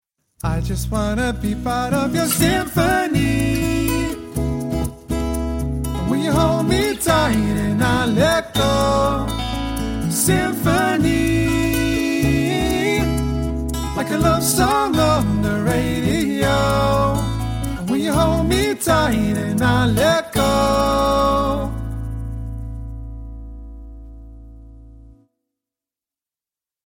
rhythmic and melodic acoustic act
• Features male vocal harmonies